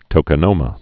(tōkə-nōmə)